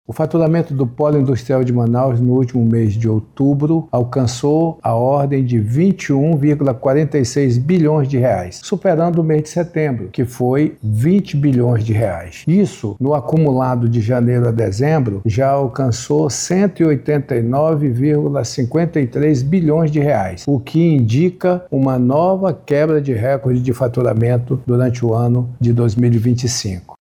Ainda nesta terça-feira 02/12, a Superintendência da Zona Franca de Manaus – Suframa divulgou um segundo recorde consecutivo de faturamento do Polo Industrial de Manaus – PIM, neste ano, explicou o superintende da autarquia, Bosco Saraiva.